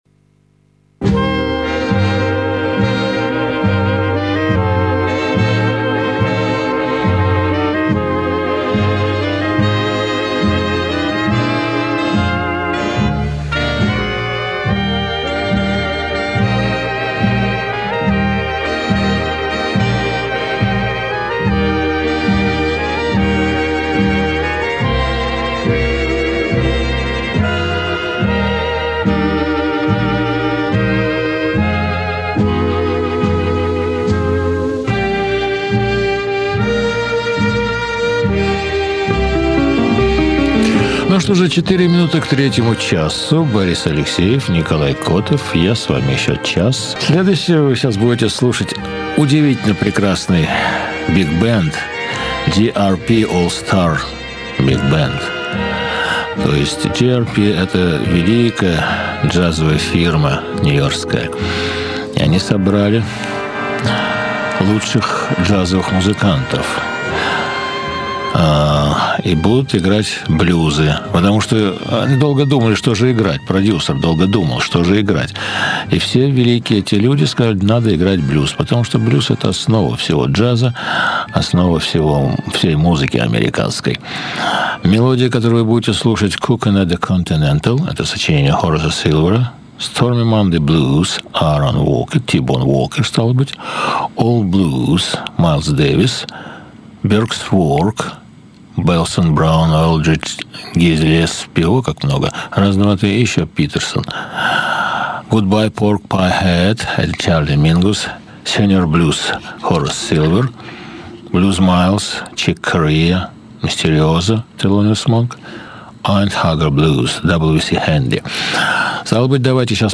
Играют выдающиеся джазовые музыканты
Он же и комментирует.
Studio album
Jazz; Big- band